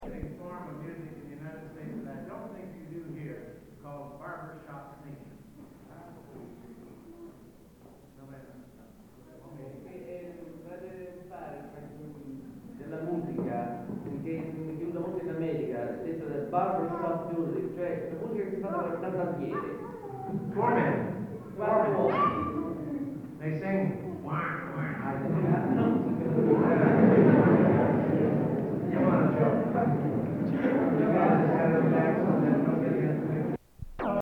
Home Sweet Home Barbershop Tune
Location: Florence, Italy
Genre: | Type: Director intros, emceeing